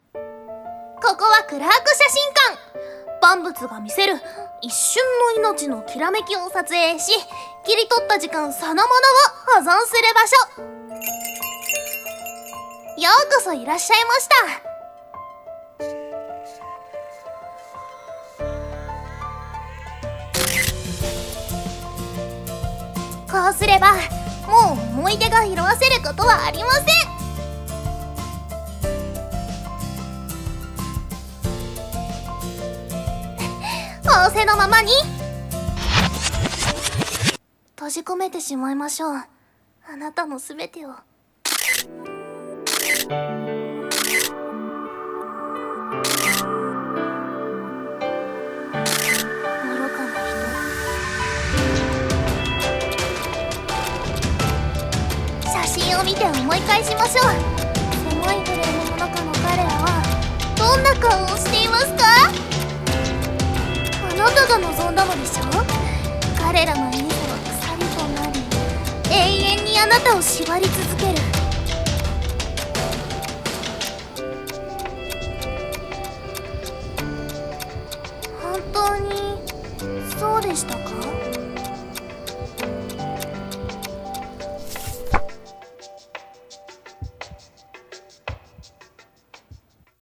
【声劇台本】忘却フォトグラファー【2人用】